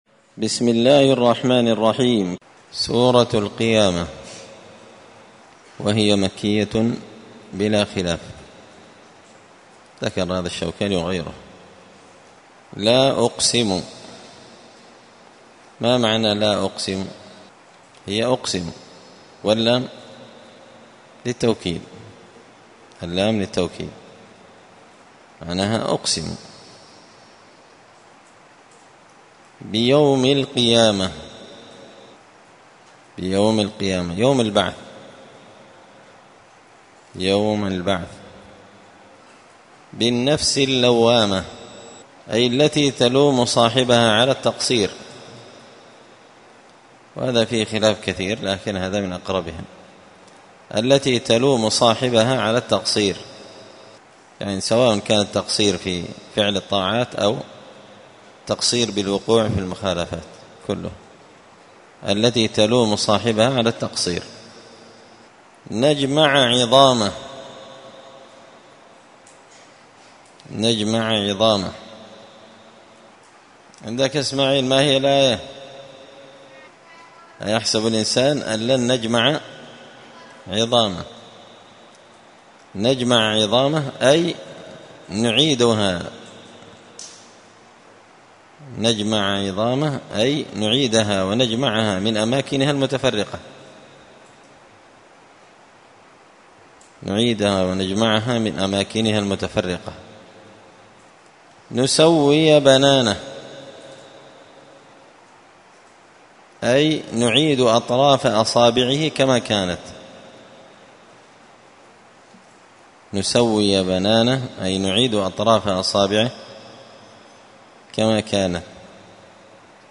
مسجد الفرقان قشن_المهرة_اليمن
101الدرس-الواحد-بعد-المائة-من-كتاب-زبدة-الأقوال-في-غريب-كلام-المتعال.mp3